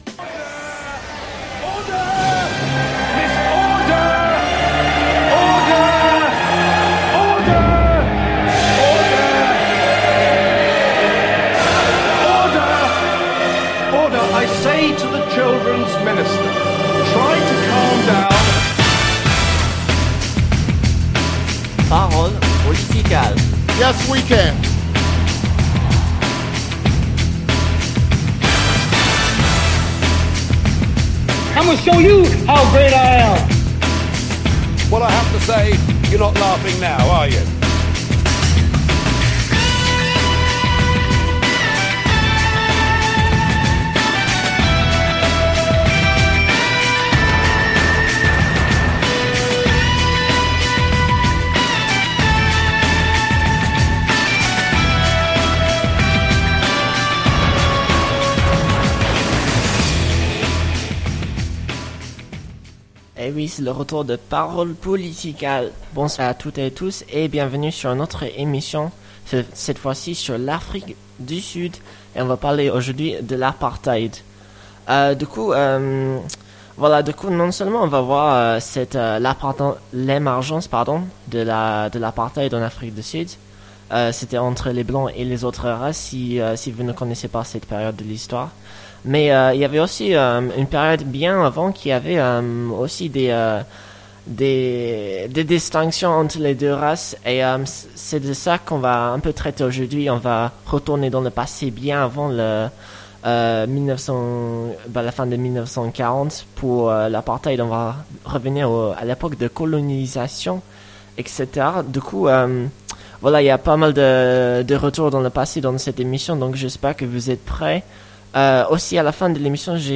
Une émission musicale-politique! Résumé de l'émission : L'émission d'aujourd'hui est sur l'Apartheid en Afrique du Sud, qui dura plus de 40 ans.